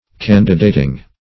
Search Result for " candidating" : The Collaborative International Dictionary of English v.0.48: Candidating \Can"di*da`ting\, n. The taking of the position of a candidate; specifically, the preaching of a clergyman with a view to settlement.